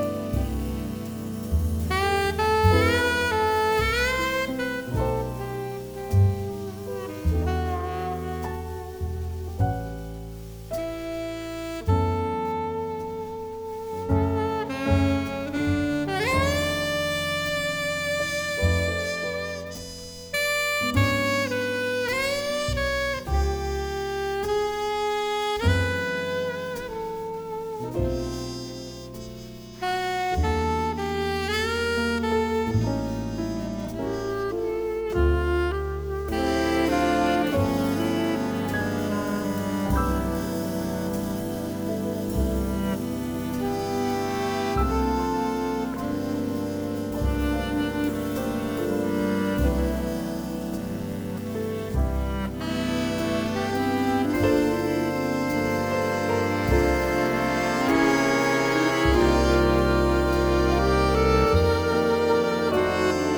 The Best In British Jazz